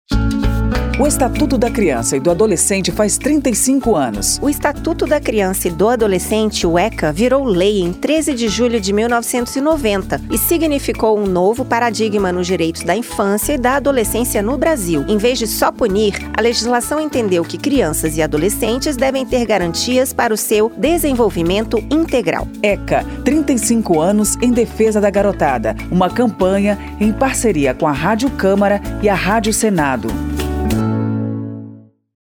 06-spot-eca-35-anos-parceiras.mp3